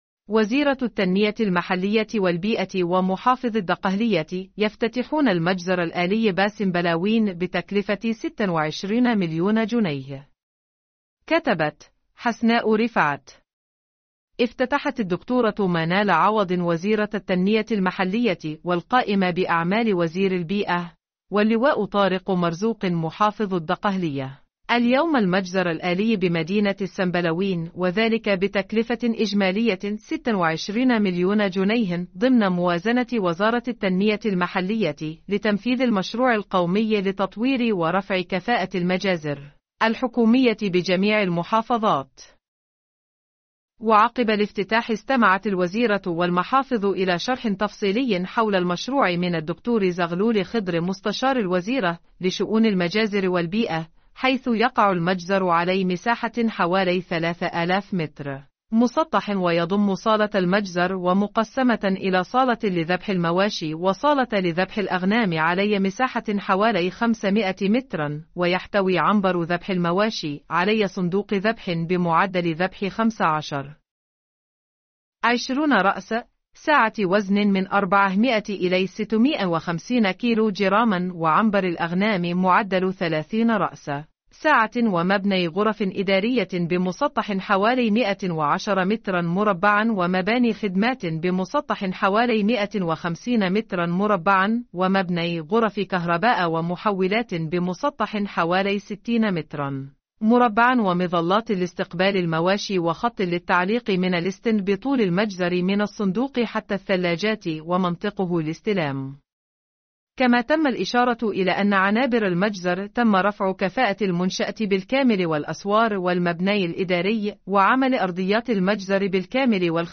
نشرة صوتية..